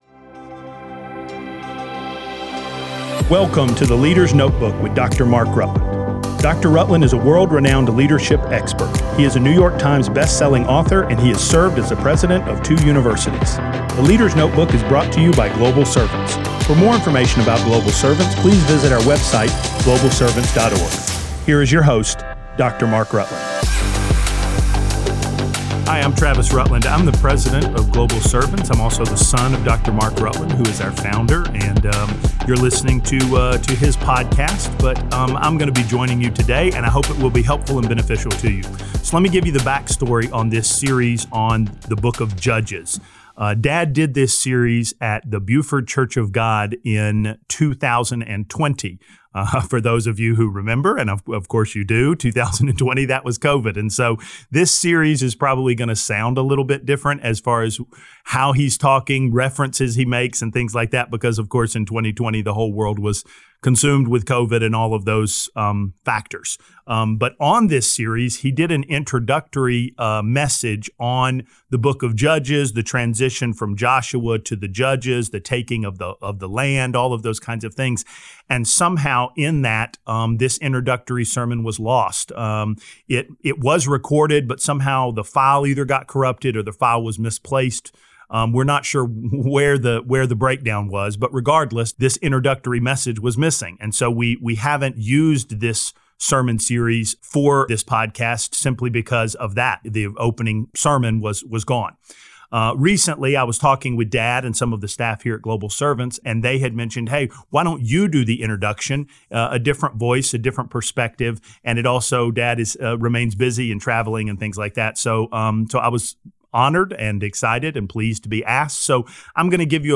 steps in as a guest speaker